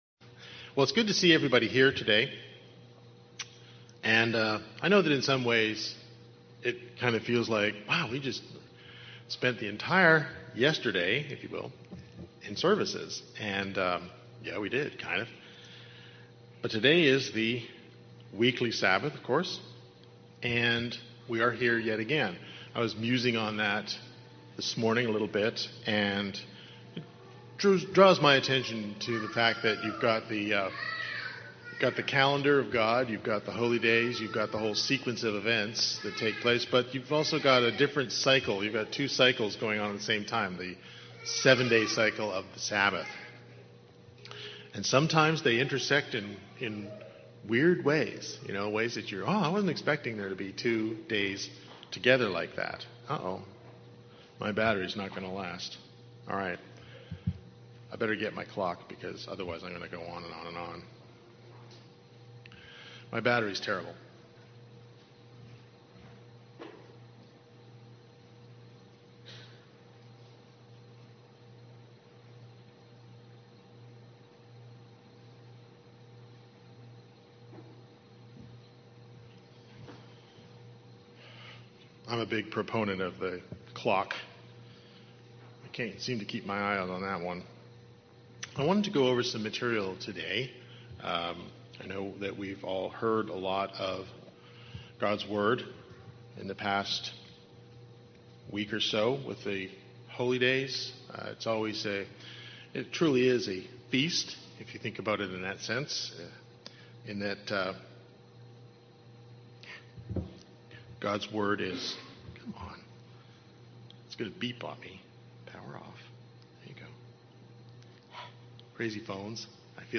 Sermon
Given in Raleigh, NC